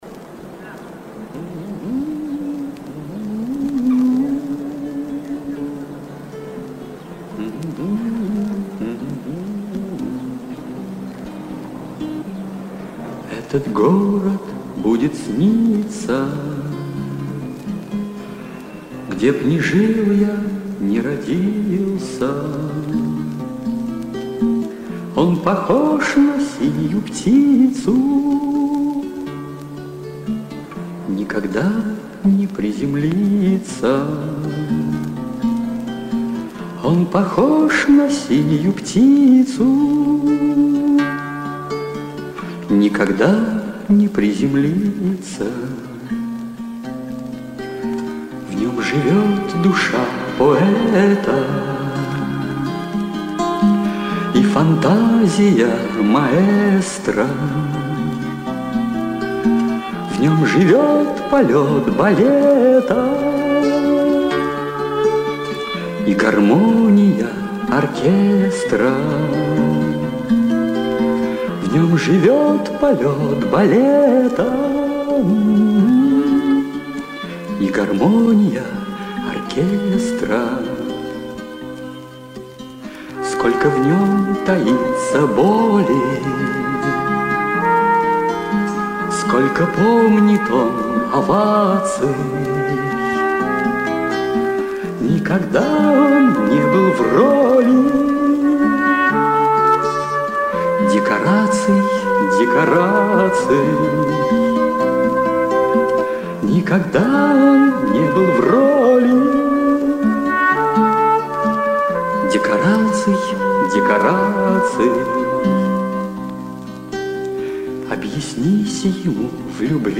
Песня из телефильма
(запись из фильма)